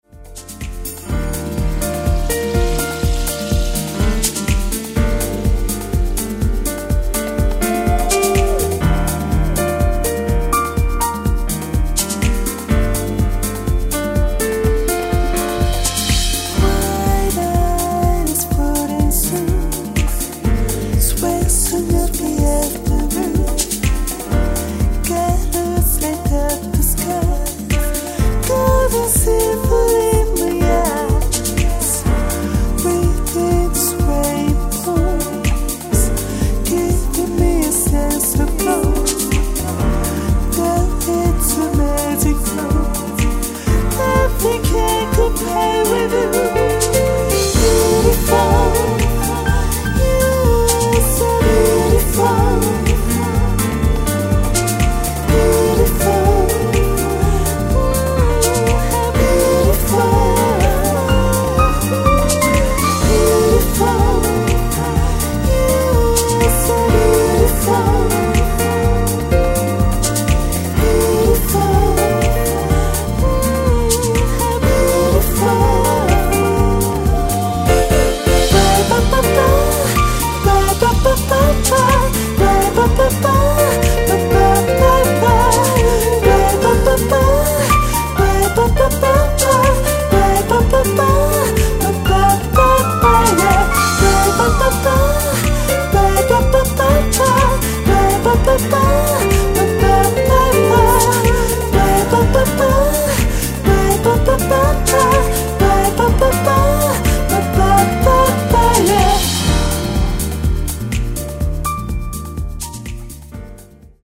癒しのファルセット・ヴォイス。
アルバムのオープニングを飾るインスト。